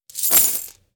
coin_drop_02.wav.mp3